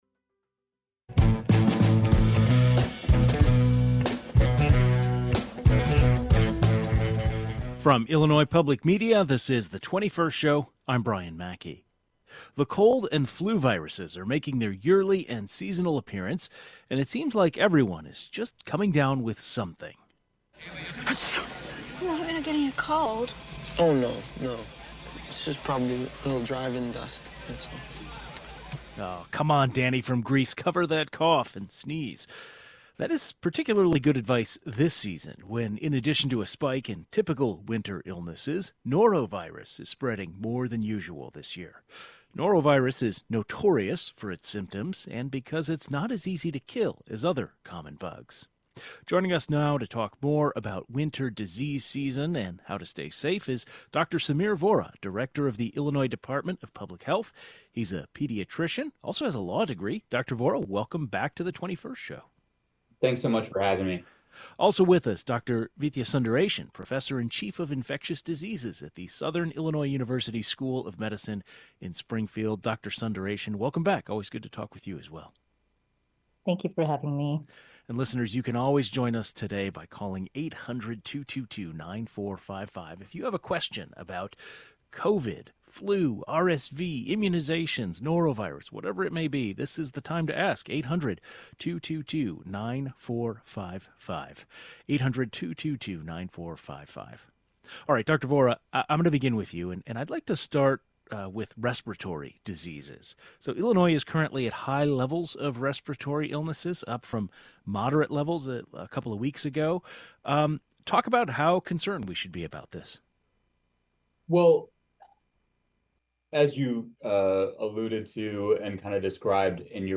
The head of Illinois's health department and a professor, who specializes in infectious dieases, join the program to discuss vaccines, symptoms, and how to prevent the spread of some of these viruses.